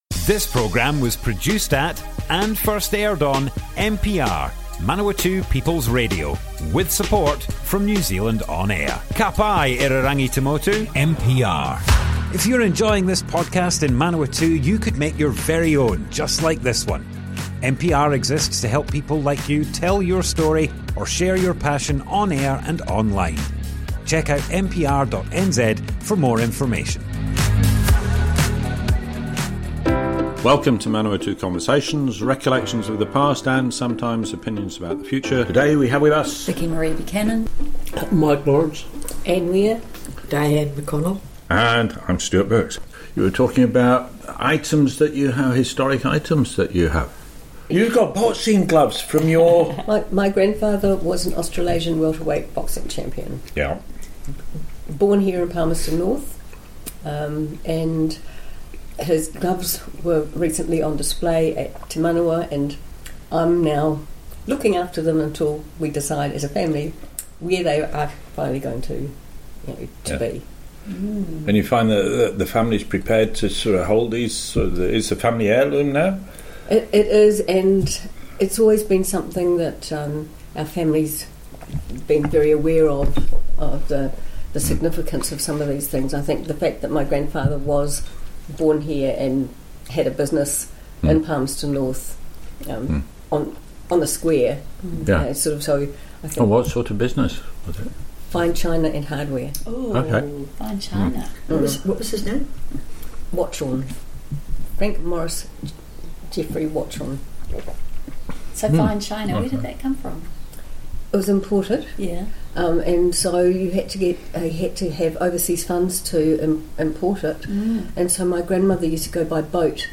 Group discussion, when we were younger, Part 1 - Manawatu Conversations - Manawatū Heritage
00:00 of 00:00 Add to a set Other Sets Description Comments Group discussion, when we were younger, Part 1 - Manawatu Conversations More Info → Description Broadcast on Manawatu People's Radio, 27th May 2025.